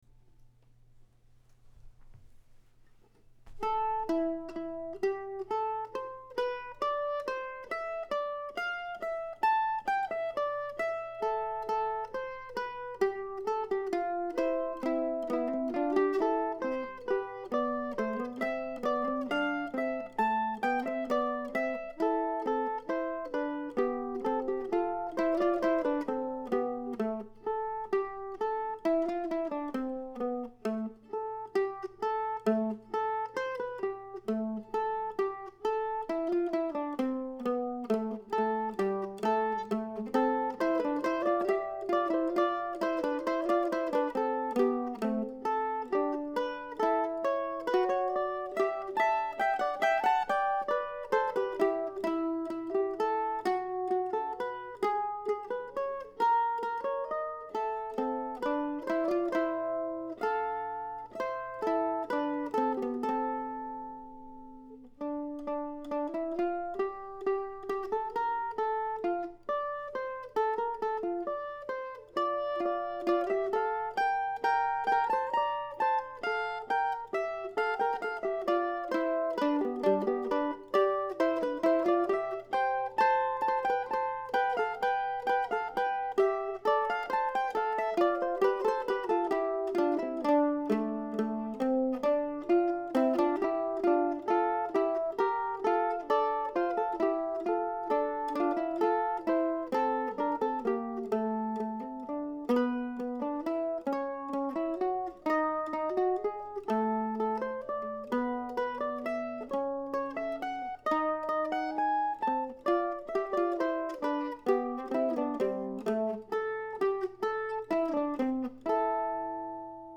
Back in 2017 I wrote a little duo for two mandolins.